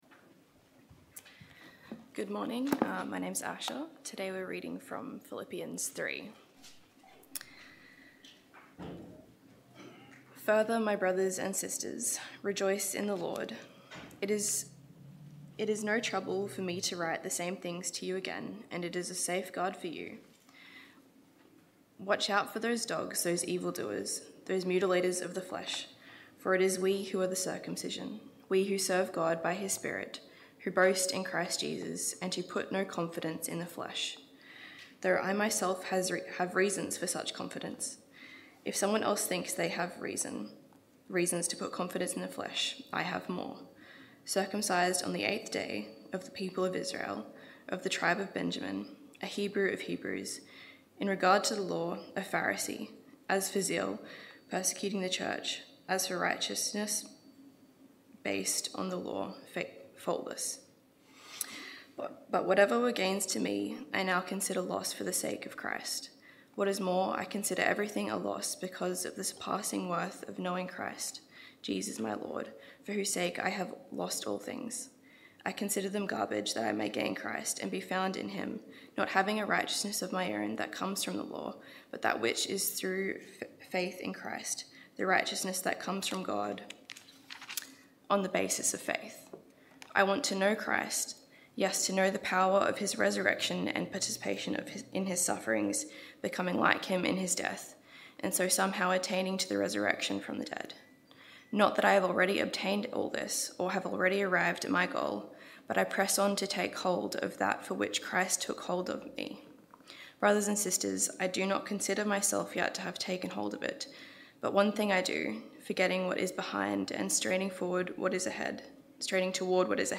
Sermons | Dickson Baptist Church
Guest Speaker